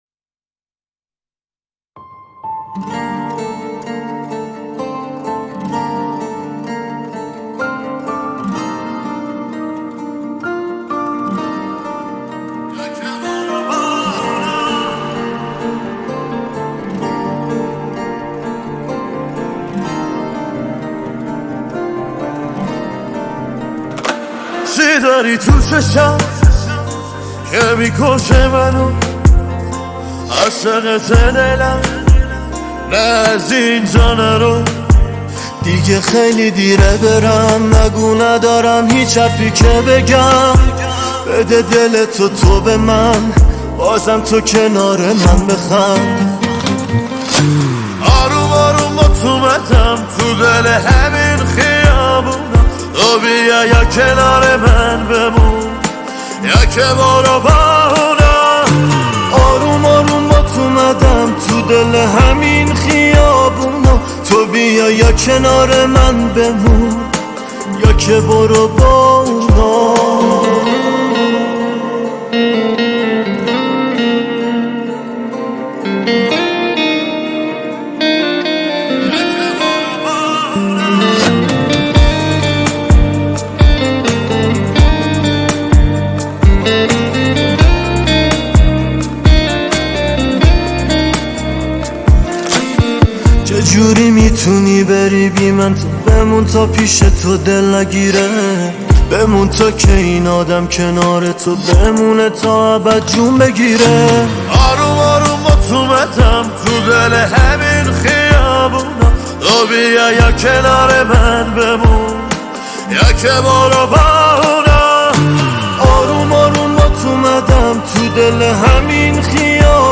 آهنگ فارسی